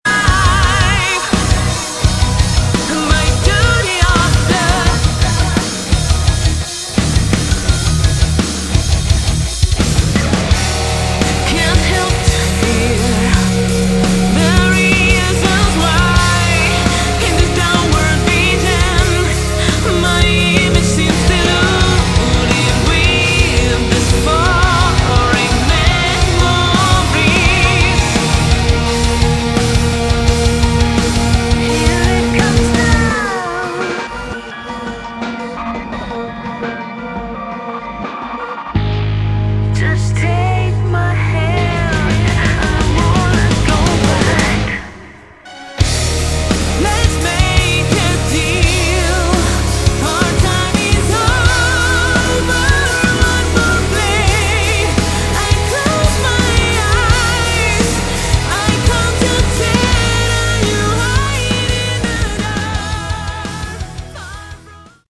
Category: Melodic Metal
vocals
keyboards
drums
guitars
bass